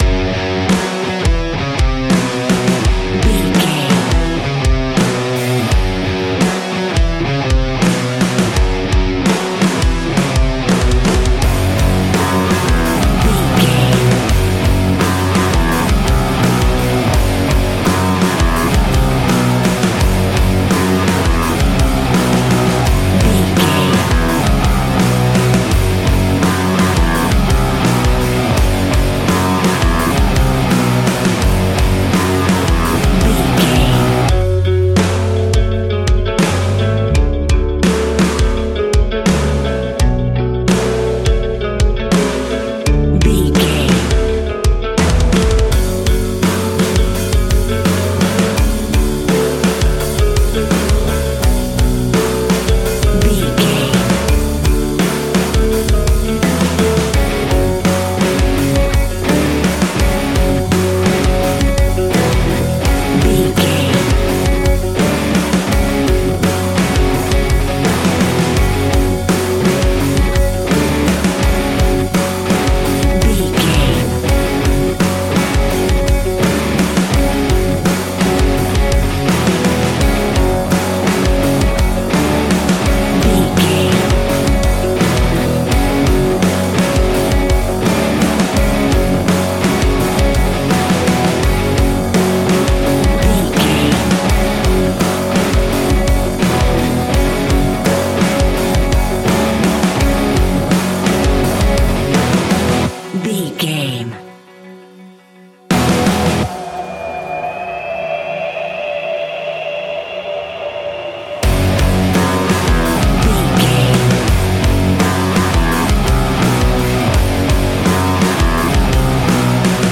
Ionian/Major
F♯
hard rock
heavy metal